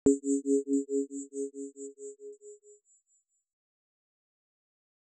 tone1.R.wav